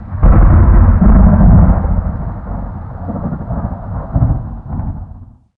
thunder26.ogg